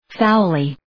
Shkrimi fonetik {‘faʋlı}
foully.mp3